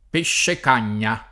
peššek#n’n’a], anche più spreg., nel dim. pescecanino (col femm. -canina o -cagnina), nei der. pescecanesco e pescecanismo — cfr. pesce